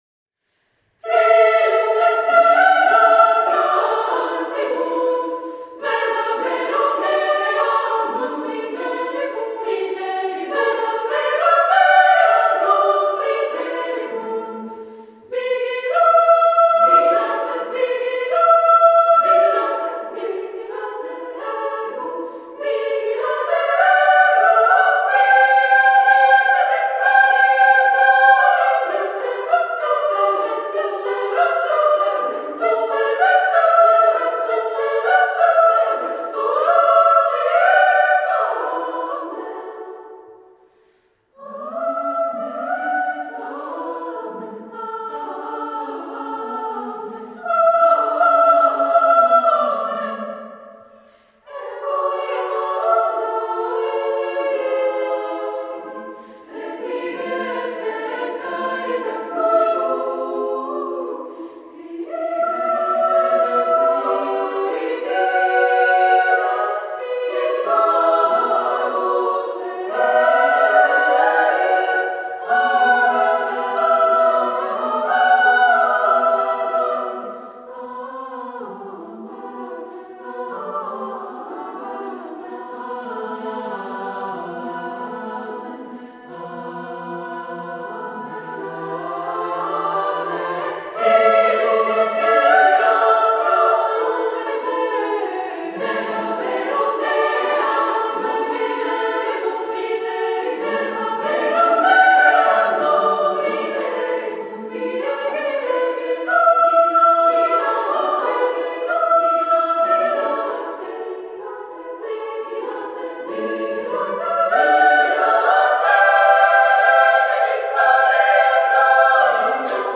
for female choir